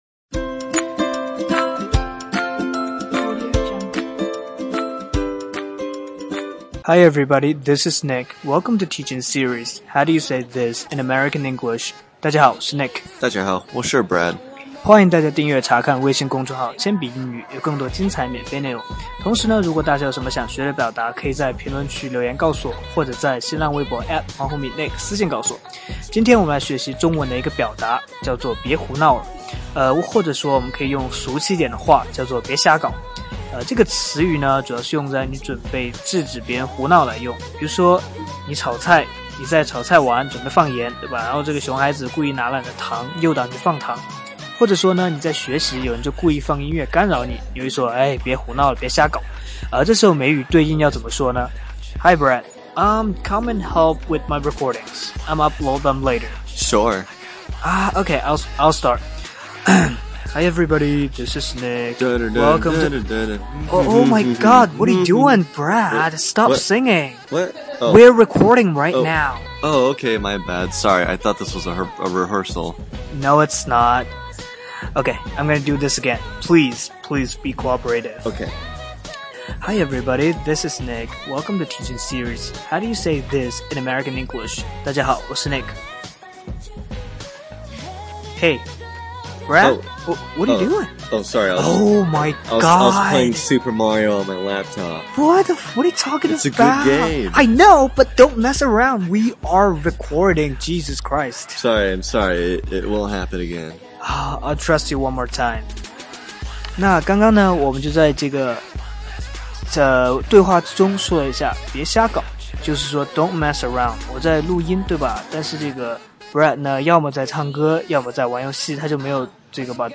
在线英语听力室全网最酷美语怎么说:第43期 别胡闹了的听力文件下载, 《全网最酷美语怎么说》栏目是一档中外教日播教学节目，致力于帮大家解决“就在嘴边却出不出口”的难题，摆脱中式英语，学习最IN最地道的表达。